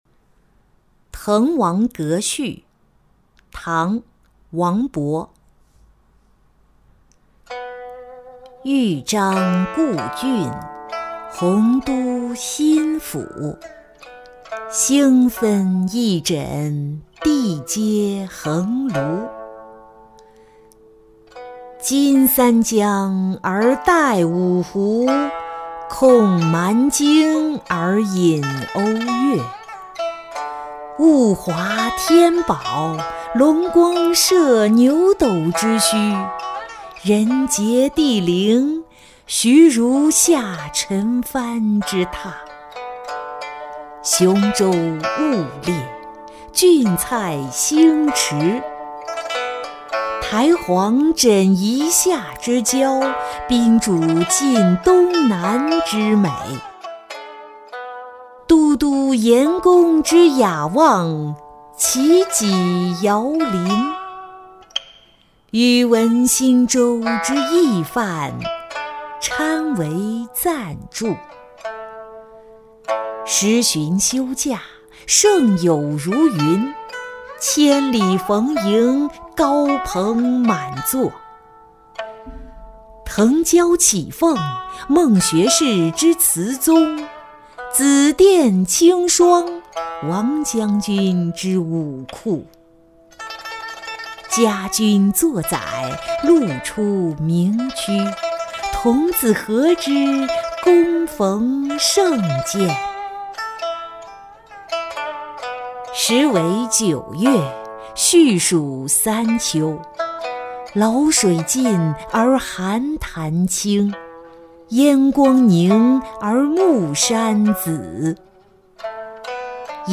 滕王阁序-音频朗读